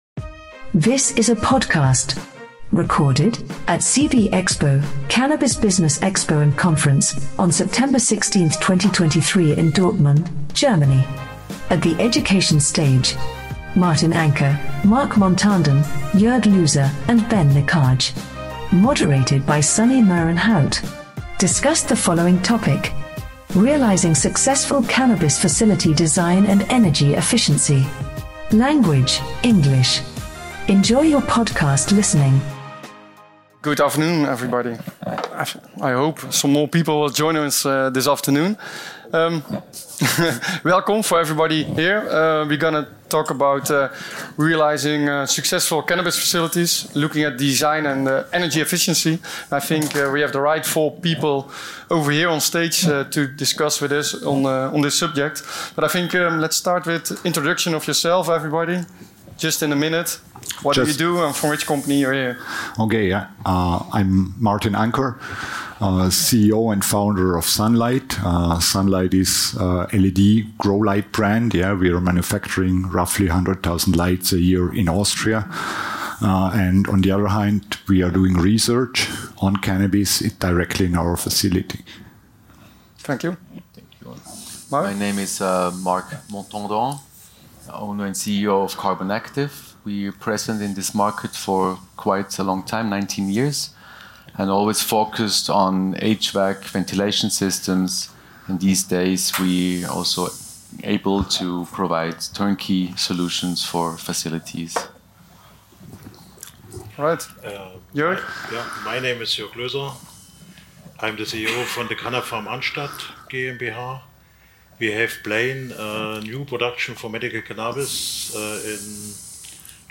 All will be discussed in this panel with experts to achieve successful discussions on facility set-up.